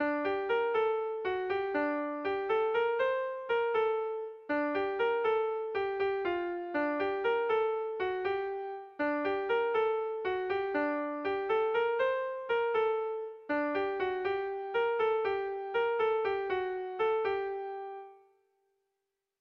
Kontakizunezkoa
Zortziko txikia (hg) / Lau puntuko txikia (ip)
ABAD